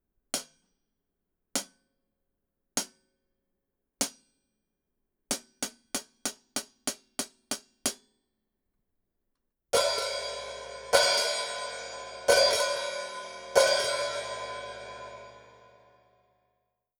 実際の録り音
ハイハット
58ドラムハット.wav